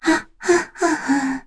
Kara-Vox_Hum.wav